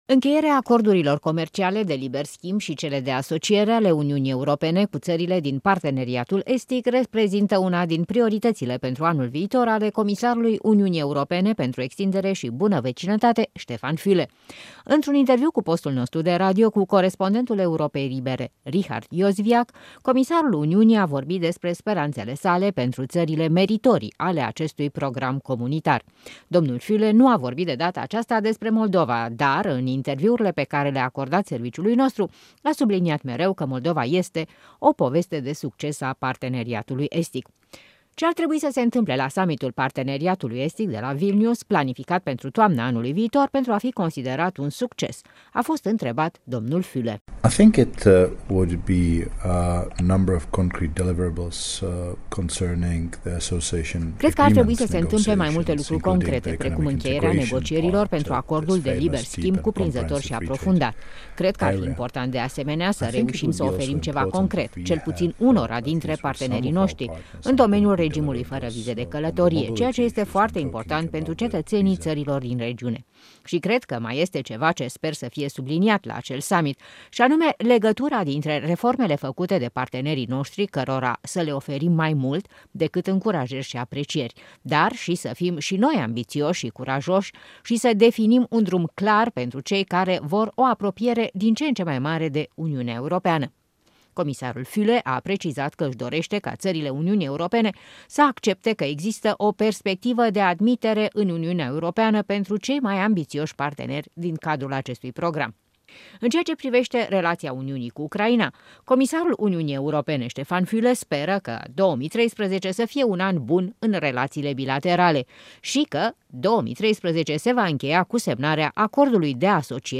Intervicu cu comisarul european Štefan Füle